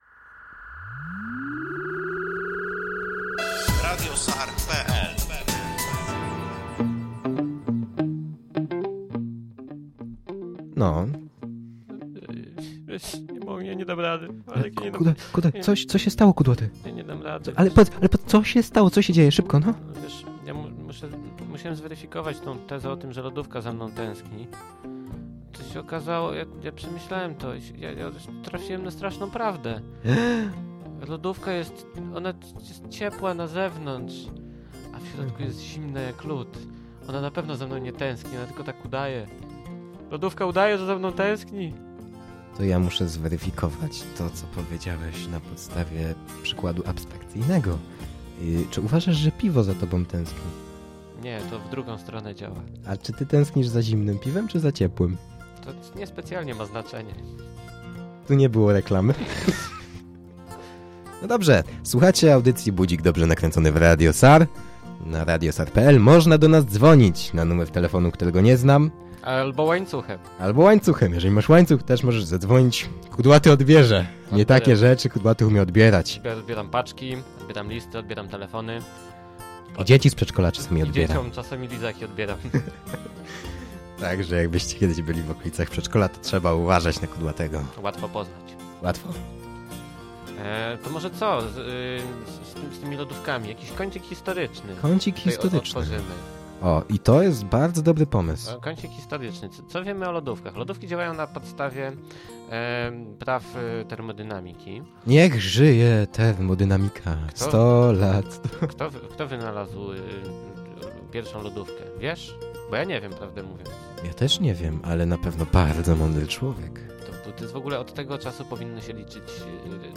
na żywo
"Co Ałtor Miał na Myśli" to audycja rozrywkowa, nagrywana co tydzień lub dwa.